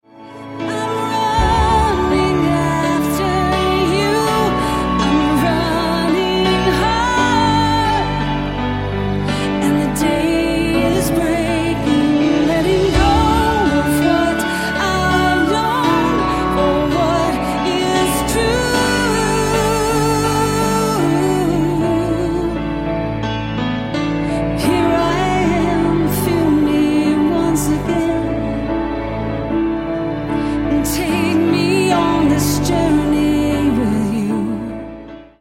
Music CD